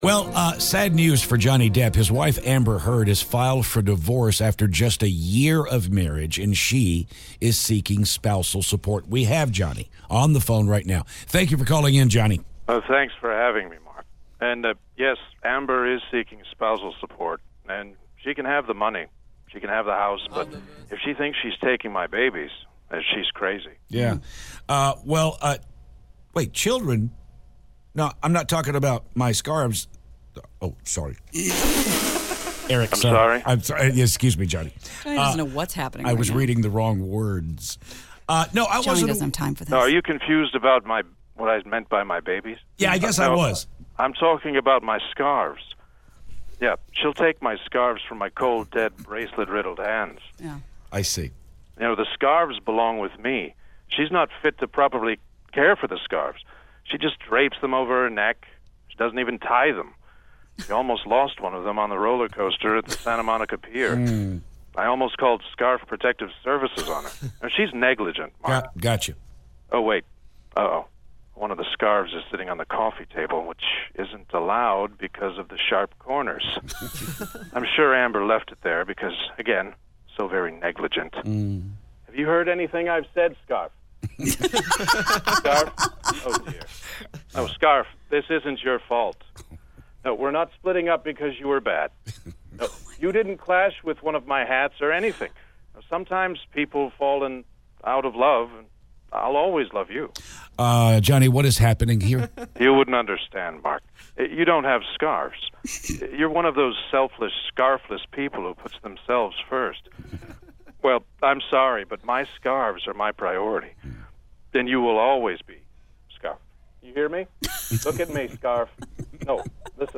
Johnny Depp Phoner
Johnny Depp calls to talk about his divorce.